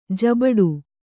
♪ jabaḍu